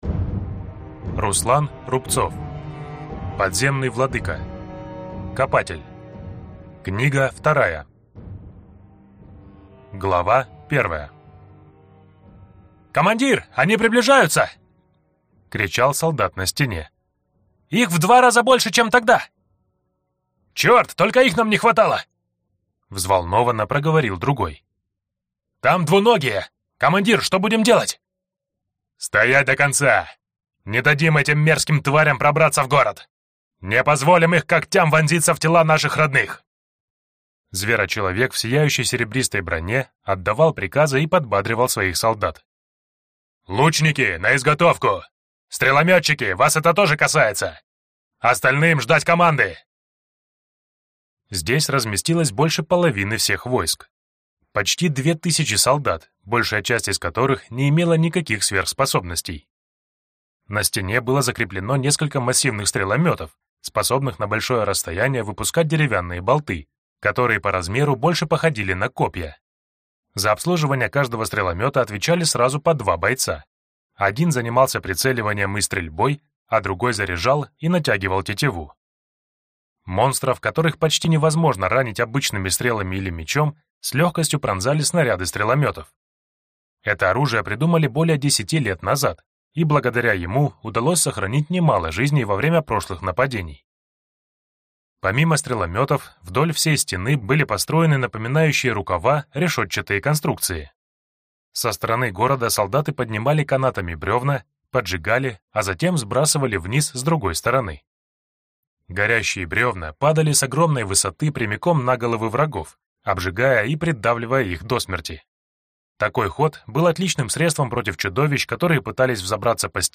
Аудиокнига Копатель. Книга 2 | Библиотека аудиокниг
Прослушать и бесплатно скачать фрагмент аудиокниги